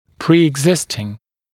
[ˌpriːɪg’zɪstɪŋ][ˌпри:иг’зистин]ранее имевшийся, существовавший